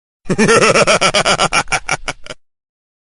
Tekken Bryan Fury Laugh Sound Button - Free Download & Play